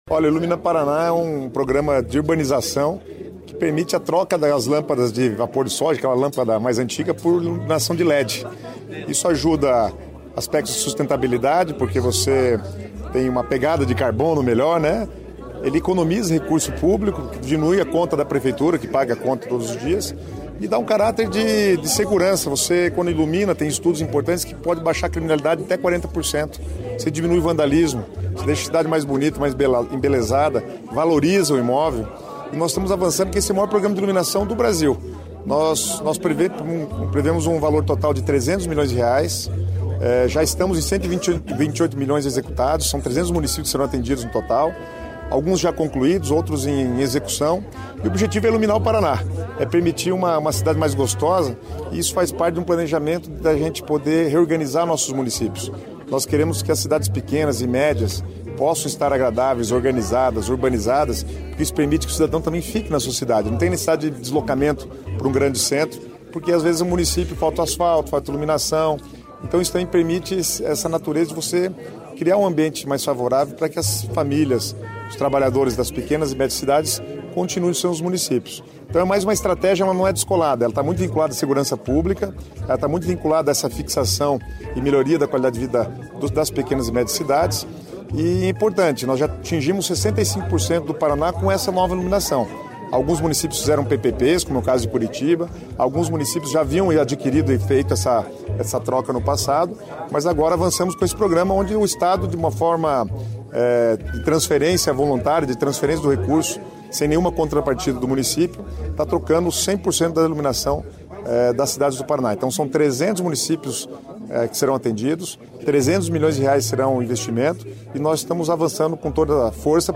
Sonora do secretário Estadual das Cidades, Guto Silva, sobre as liberações desta quarta pelo programa Ilumina Paraná